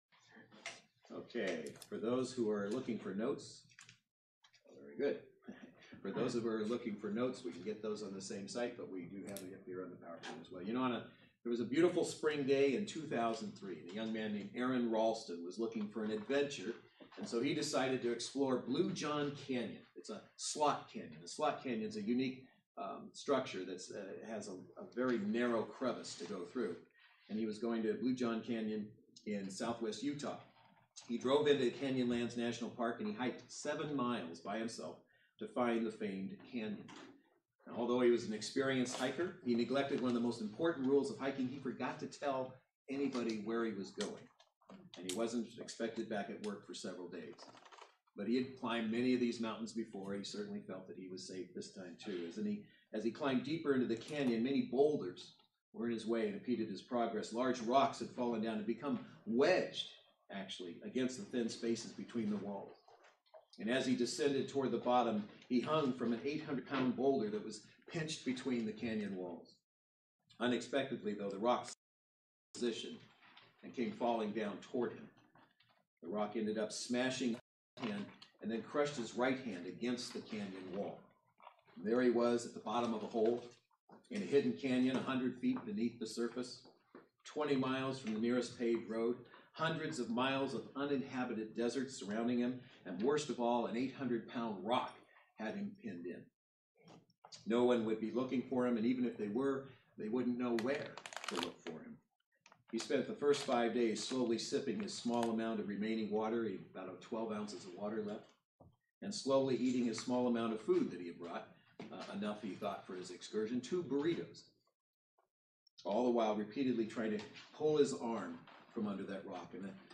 Mark 9:43-48 Service Type: Saturday Worship Service Bible Text